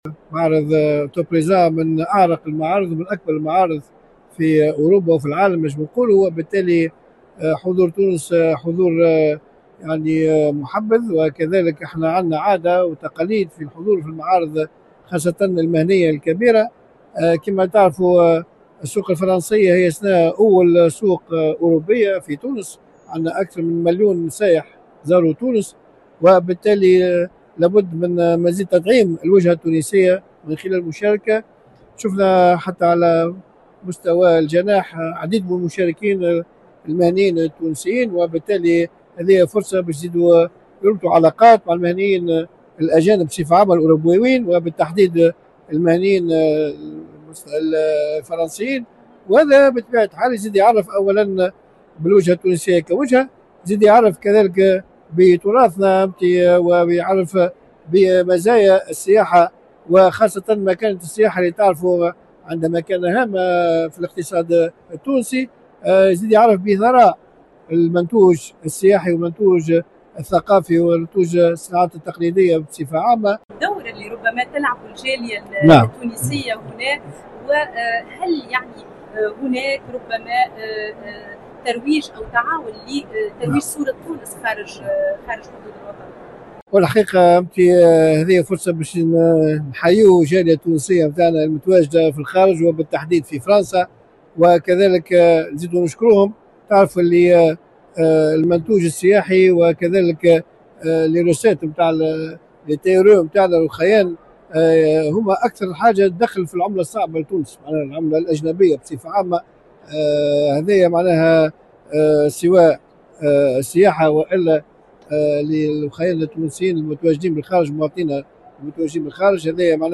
في إطار تغطيتنا لمعرض السياحة العالمي IFTM Top Resa 2025، الذي انعقد من 23 إلى 25 سبتمبر في قصر المعارض باريس بورت دو فرساي، كانت إذاعة الشرق في باريس حاضرة لمتابعة المشاركة العربية في هذا الموعد السنوي الذي يُعد من بين الأهم والأكثر تأثيراً في مجال السياحة الدولية.